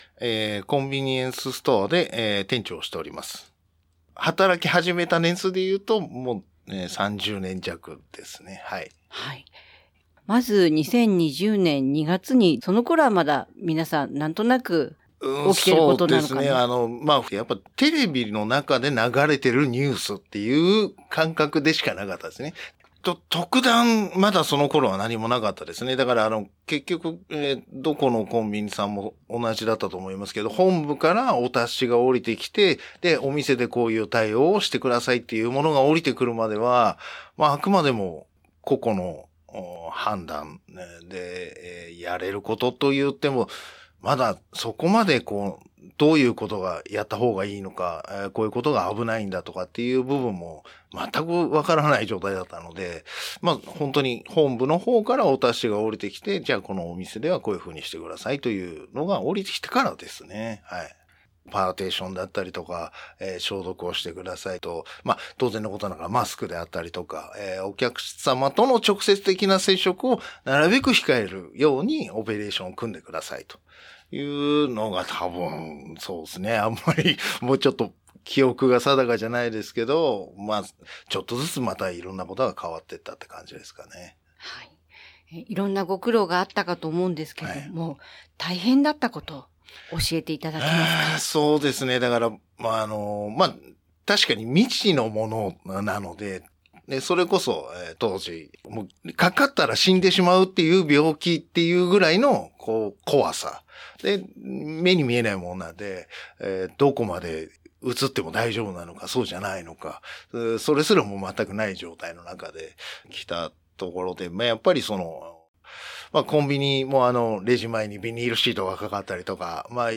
30年近く現場に立ち続ける店長が語るのは、未知の感染症への恐怖と、接客の現場で起きた戸惑い、そして「休めない仕事」としての責任。その経験の先に見えてきたのは、過剰に恐れることでも、無関心でいることでもない——社会としての“ちょうどいい距離感”の必要性だった。